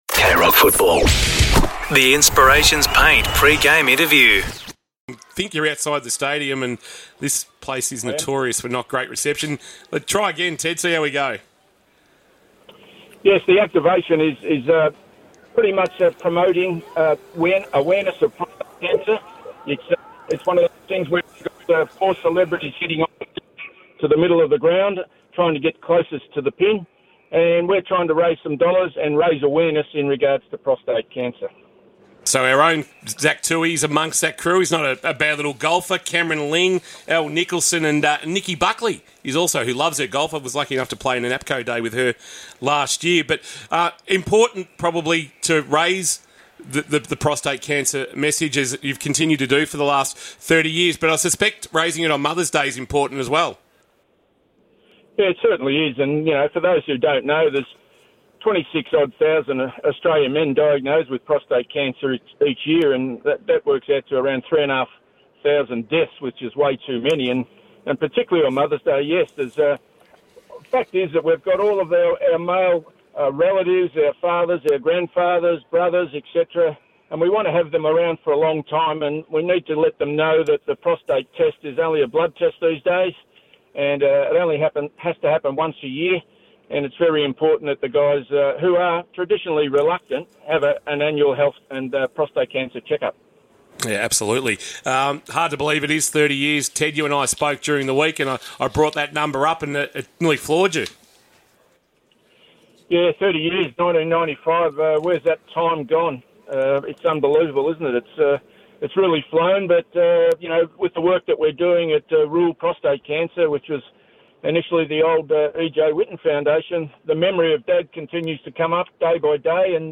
2025 - AFL - Round 9 - Geelong vs. GWS: Pre-match interview